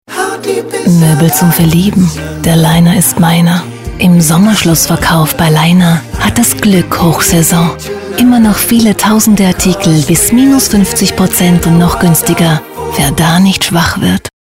deutsche Sprecherin.
Sprechprobe: eLearning (Muttersprache):
german female voice over artist, dubbing